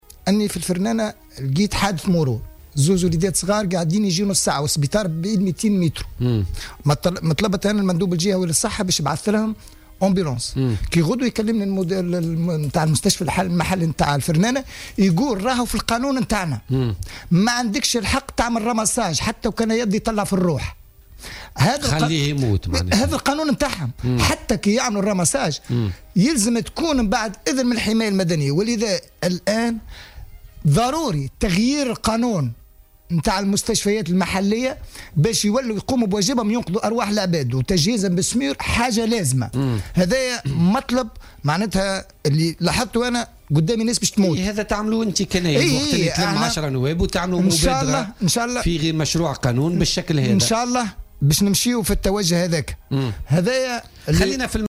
وأوضح ضيف "بوليتيكا" أنه رغم مرور أكثر من نصف ساعة على الحادث وقرب مستشفى فرنانة من مكان الحادث (يبعد فقط نحو 200 متر) الا ان المستشفى رفض التدخل على الفور لانقاذ الطفلين.